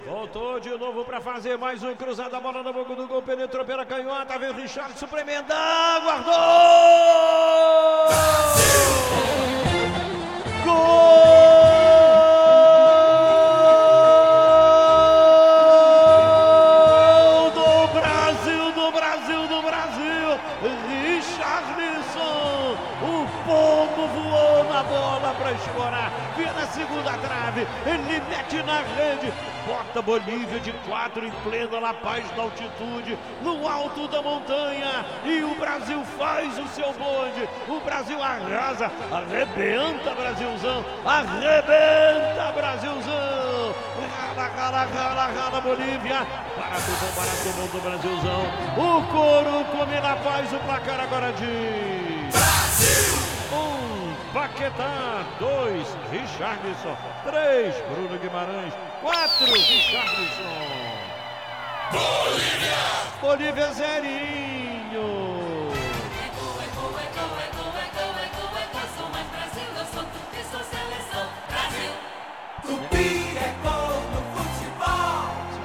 Ouça os gols da vitória do Brasil sobre a Bolívia com a narração de Luiz Penido